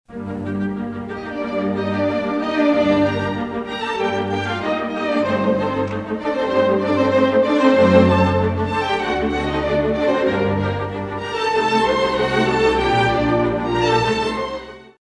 Classical Music Samples
Classical 66b